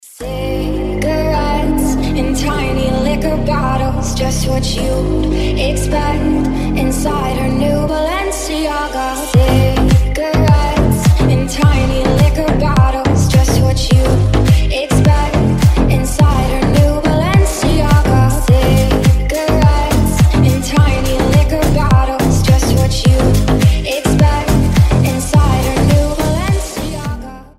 Рингтоны ремиксы
Рингтоны техно
Басы , Красивый женский голос , Deep house